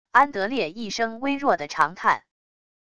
安德烈一声微弱的长叹wav音频